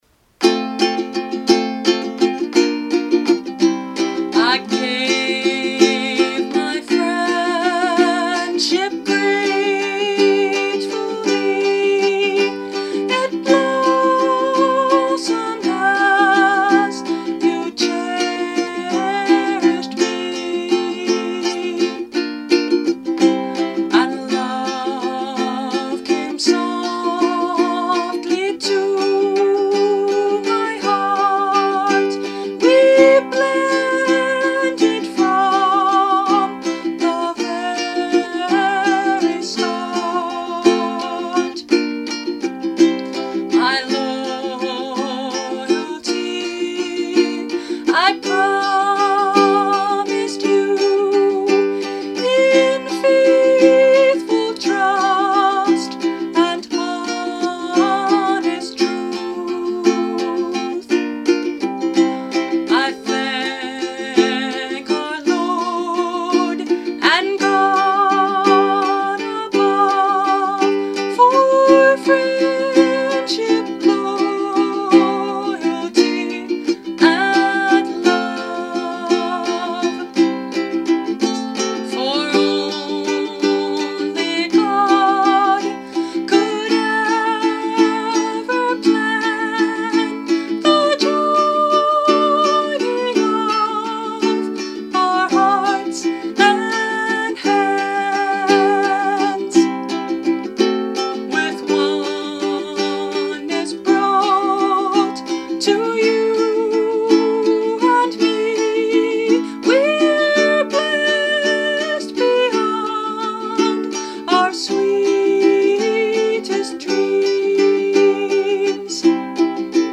This song with Celtic flavor is inspired by the Claddagh (see below) and Samhain, the Celtic New Year and beginning of Winter.